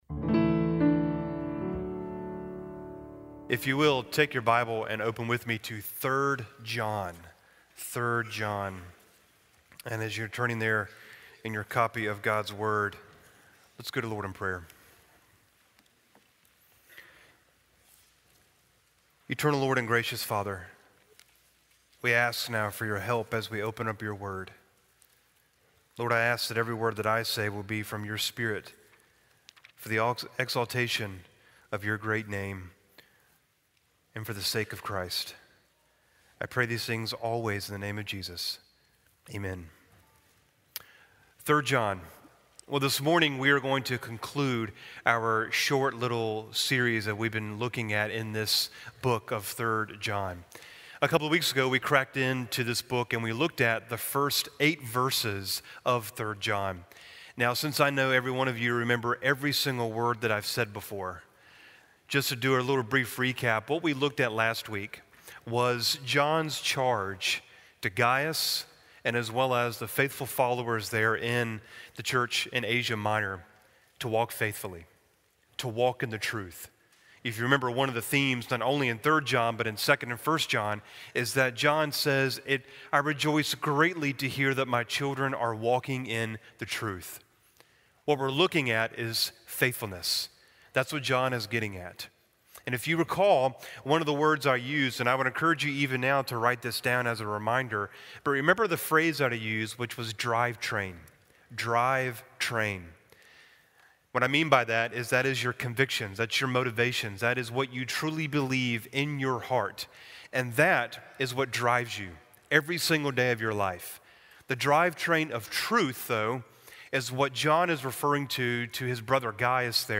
Sermons from Christ Baptist Church, in Raleigh, NC.
Christ Baptist Church Sermons